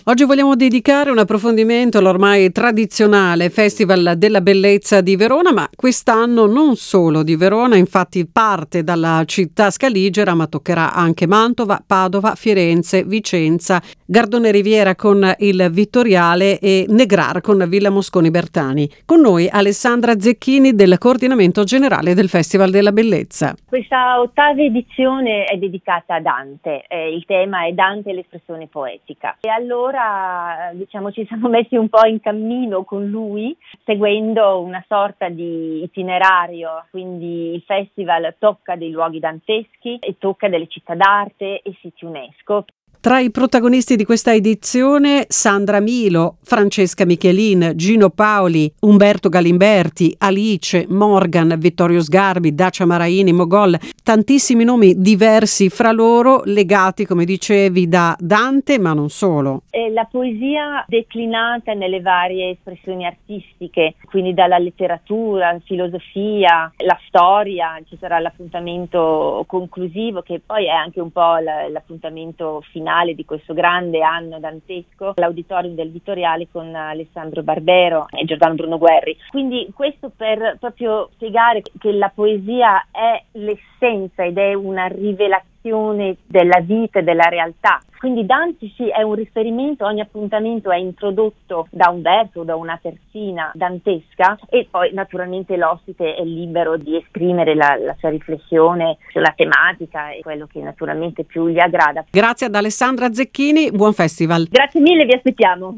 23/07/2021: il Festival della Bellezza protagonista di 30 eventi non solo a Verona per questa edizione 2021. La nostra intervista